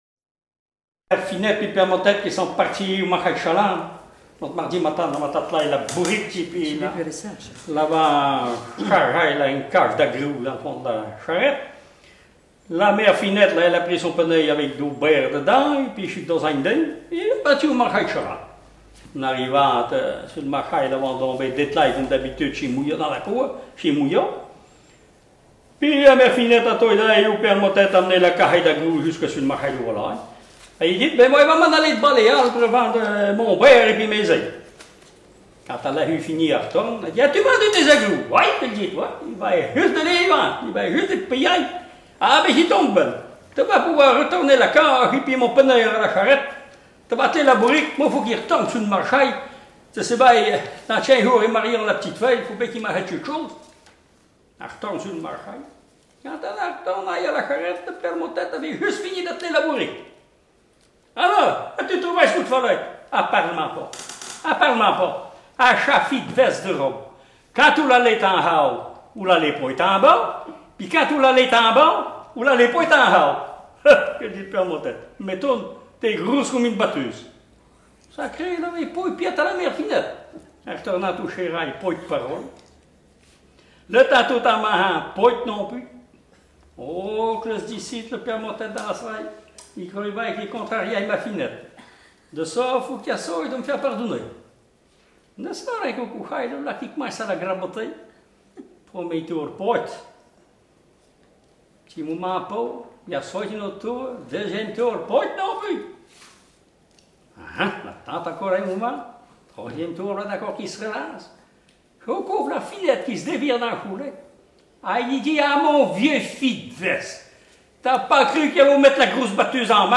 Langue Maraîchin
Genre sketch
Catégorie Récit